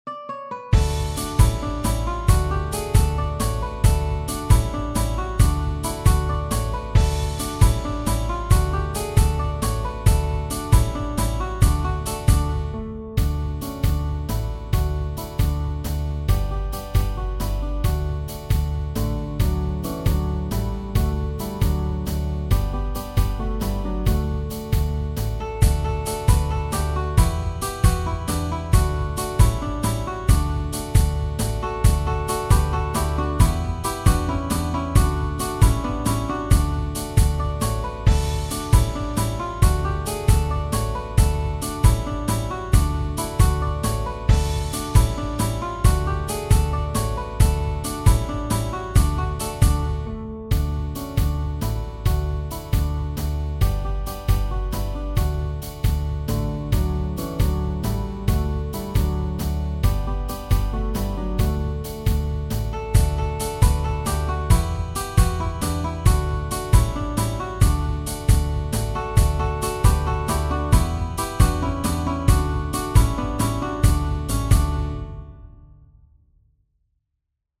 Tradizionale Genere: Folk Testo di anonimo Traslitterazione in alfabeto latino Samiotisa, Samiotisa, pote tha pas sti Samo?